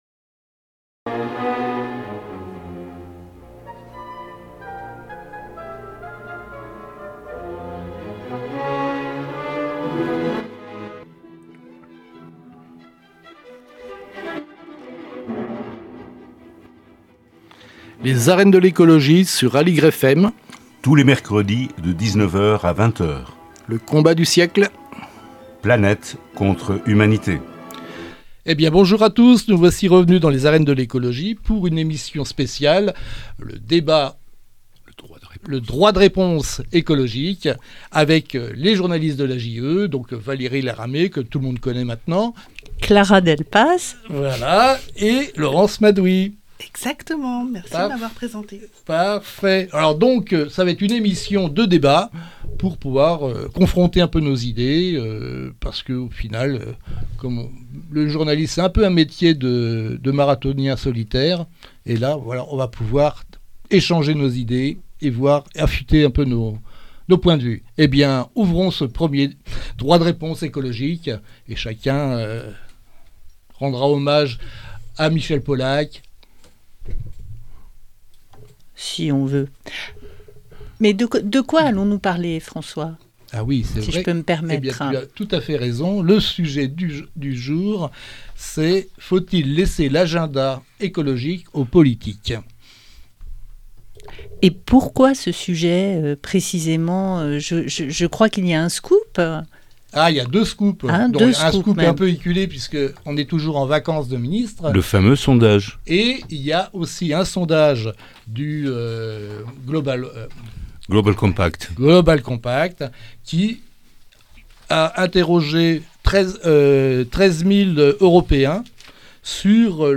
Les arènes de l'écologie #32 - Faut-il laisser l'agenda écologique aux politiques ? Grand débat de l'AJE
C'est pour rompre avec ce pseudo consensus malsain d'une écologie tombée du ciel que les Arènes de l'écologie ouvrent leurs micros aux journalistes professionnels de l'AJE.